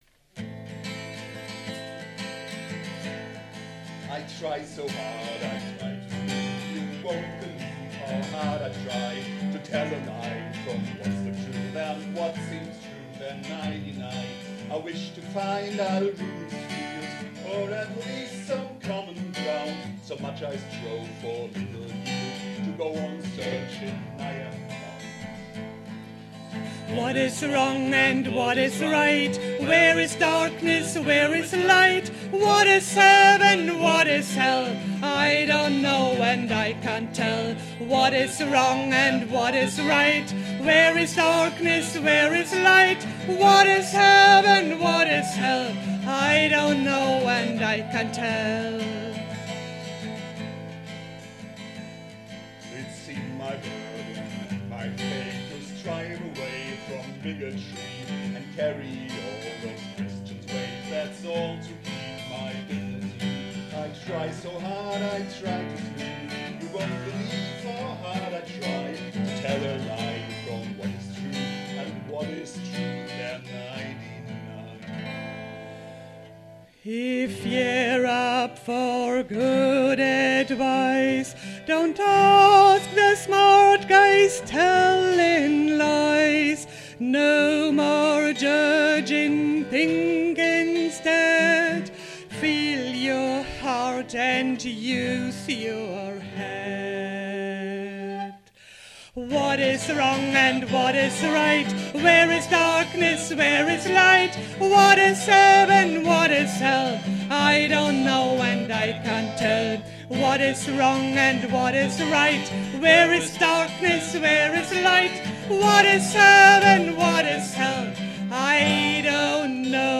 vom 9.12.22 im LALoK Gelsenkirchen-Schalke
Bad Buskers: